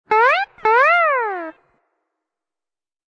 Descarga de Sonidos mp3 Gratis: guitarra a 3.